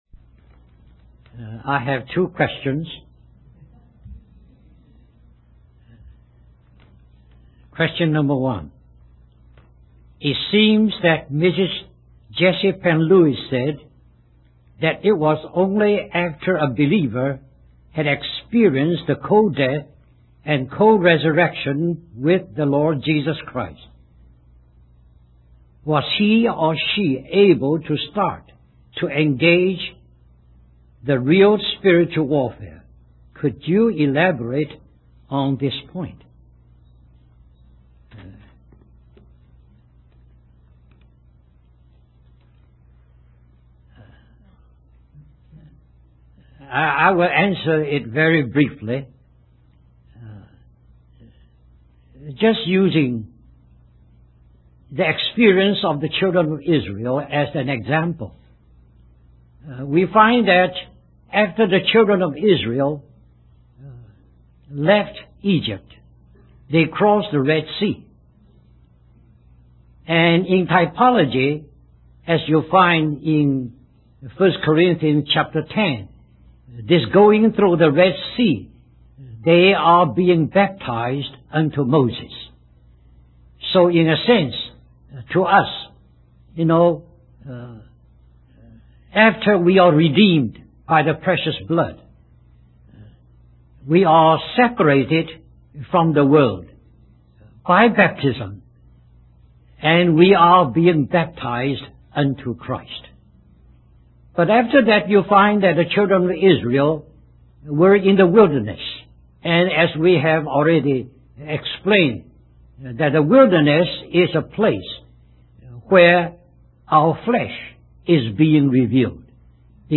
In this sermon, the speaker discusses the spiritual principle of God's purpose and plan for the world. He refers to Matthew 25, where Jesus speaks about the last days and gives a parable about the sheep and the goats.